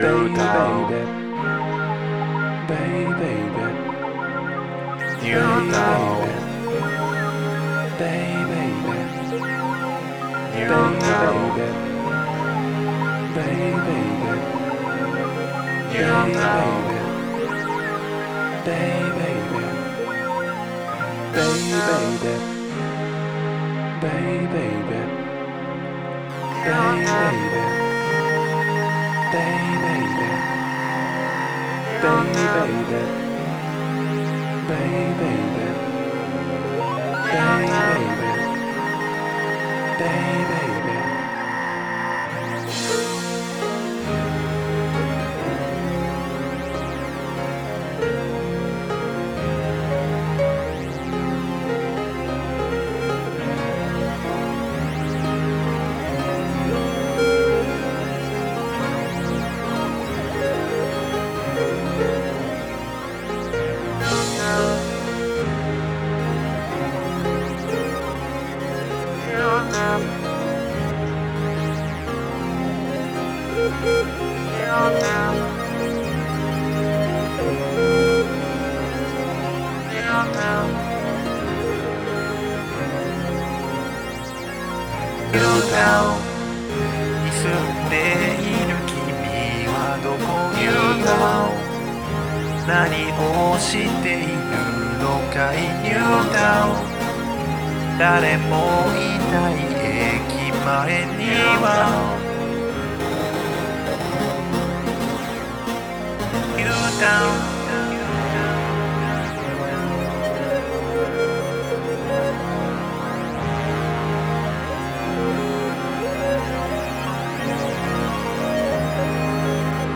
Style :J-POP